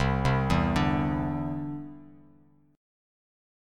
Cadd9 chord